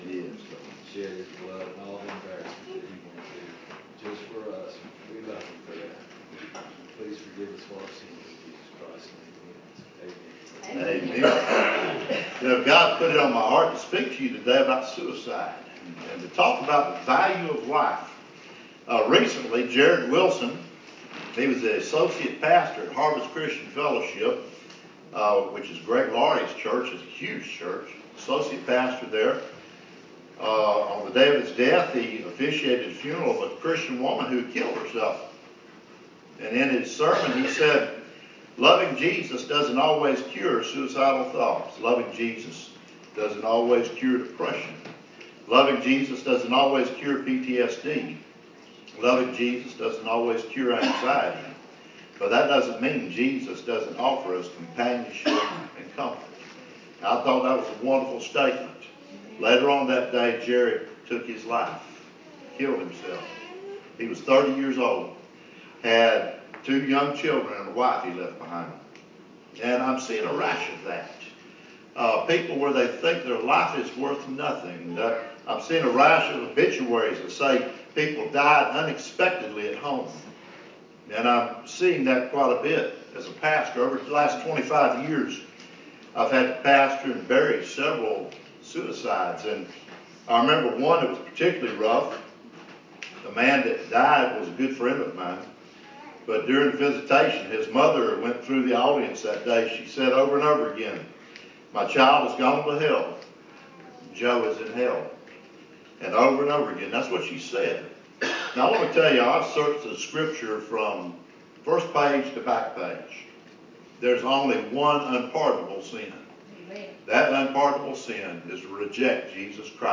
A Sermon For Suicide Prevention Sunday